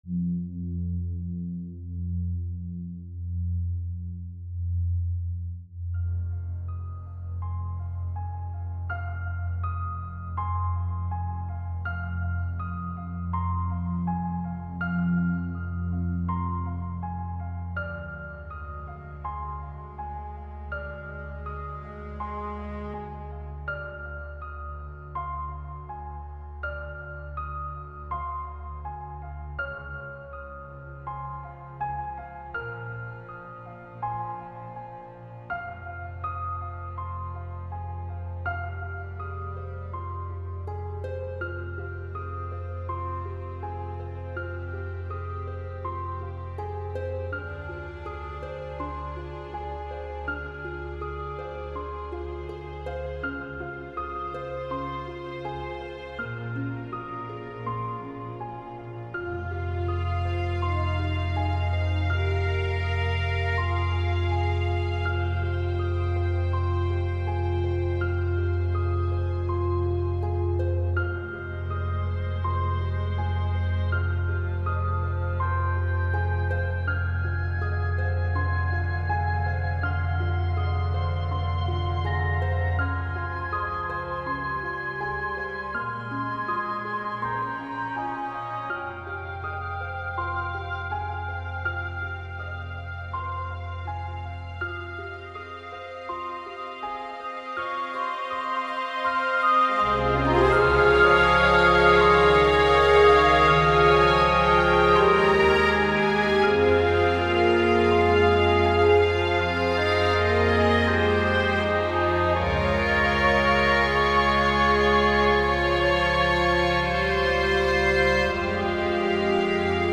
Mysterious